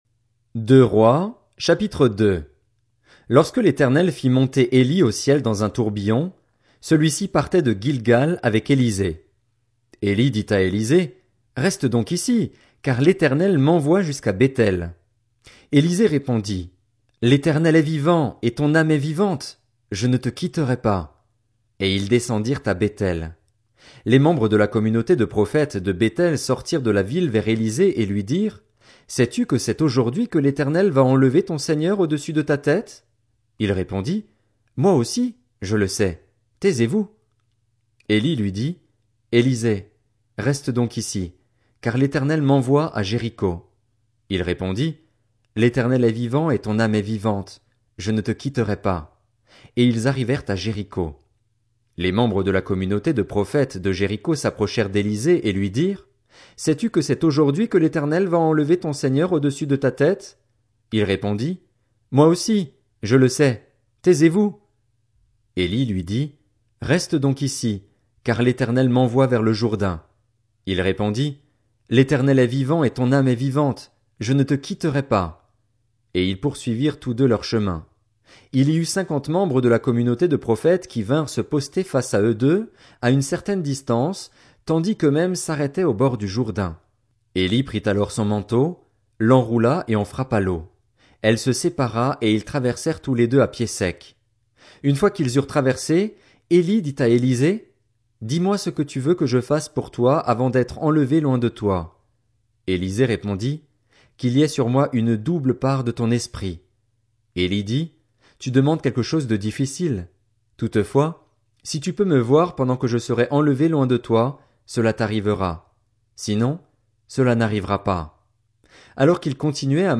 Audio Bible: Lecture chapitre par chapitre, verset par verset des livres Genèse à Job de l'Ancien Testament de la Bible Segond21.